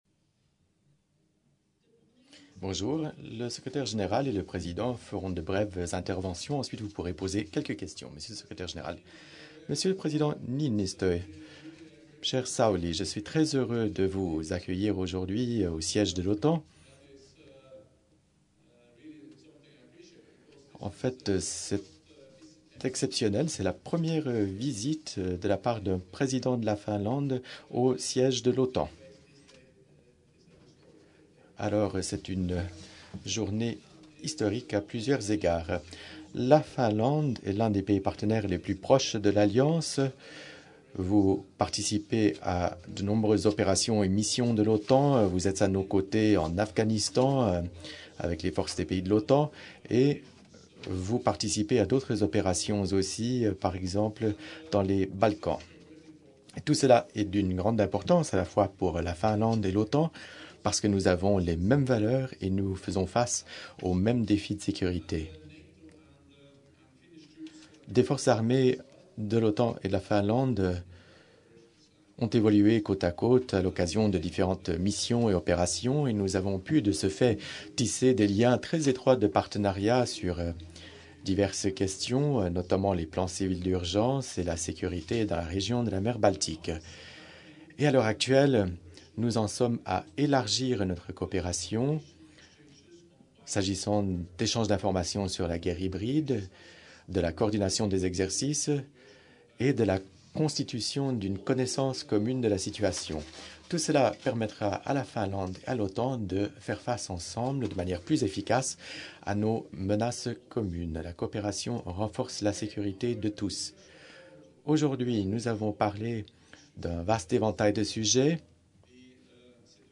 Joint press point with NATO Secretary General Jens Stoltenberg and the President of the Republic of Finland, Sauli Niinistö